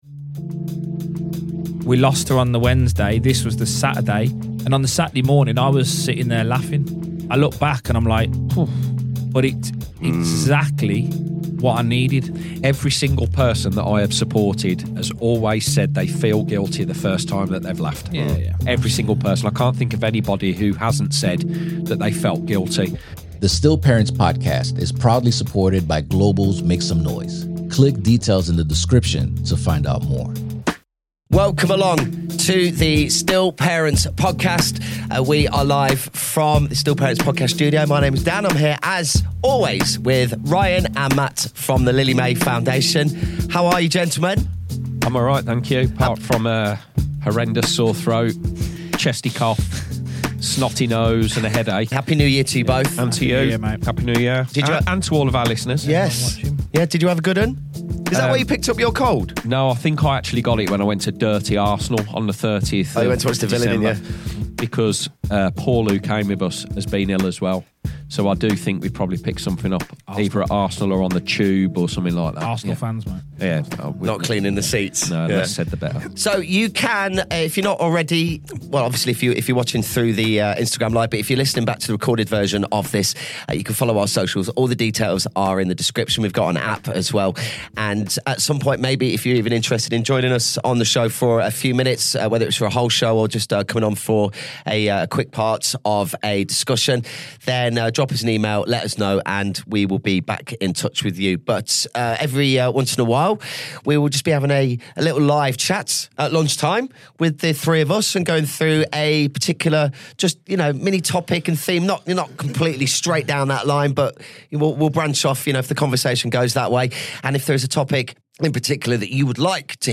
On this live episode of the Still Parents Podcast, the guys talk about how relationships with friends and family can change in unexpected ways after the devastating loss of a baby